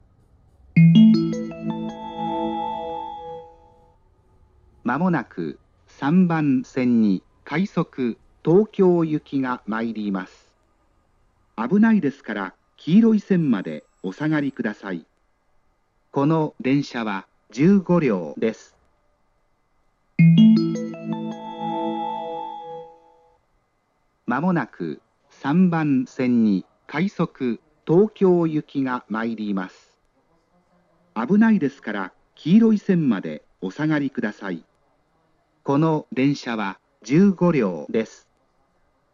発車メロディー
●音質：良